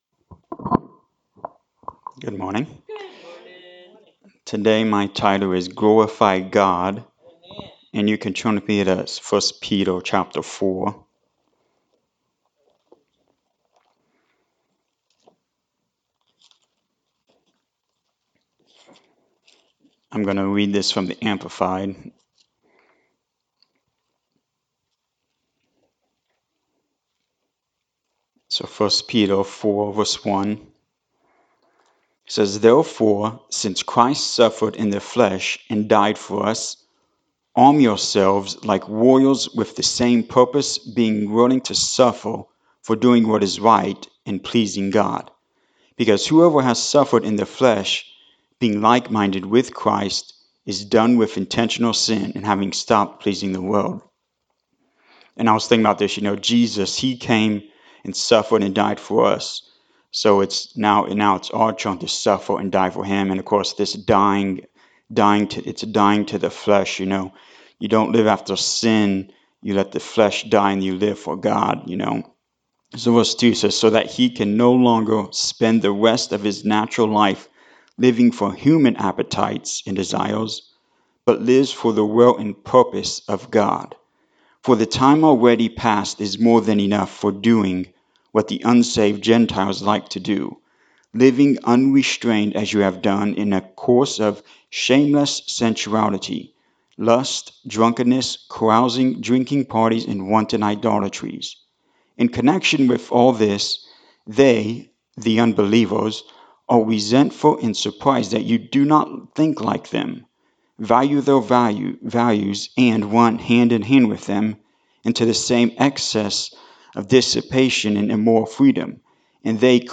As you listen to this sermon, there will be names that pop up in your mind; before you think of anyone else, start with a heart check on yourself.
Service Type: Sunday Morning Service